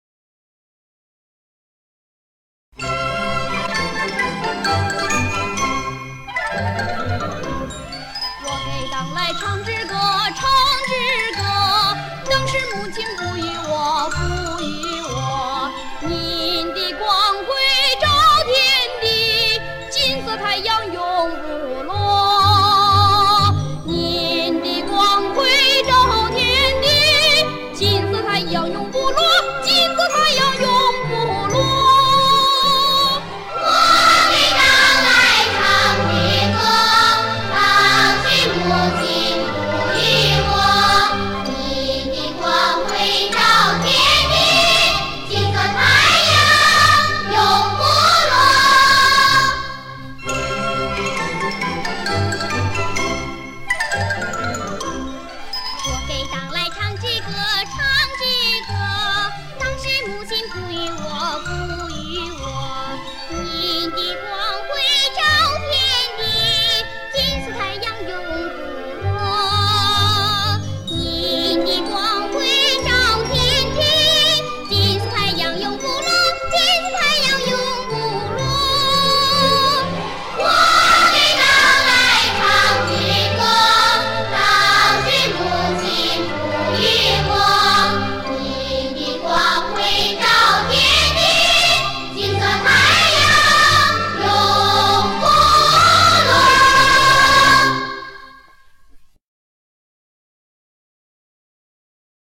[8/10/2008]文革时期的儿童歌曲《金色太阳永不落》